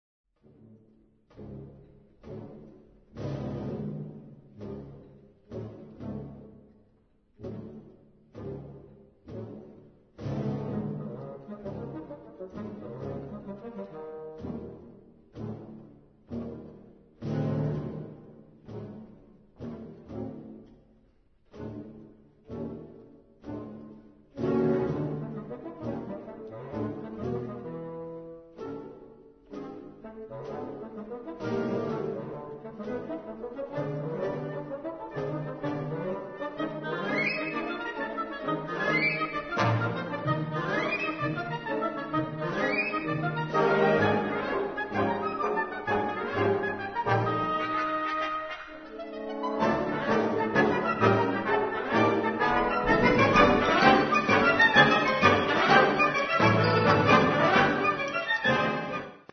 Categoria Concert/wind/brass band
Instrumentation Ha (orchestra di strumenti a faito)
Instrumentation/info (mit Akkordeon)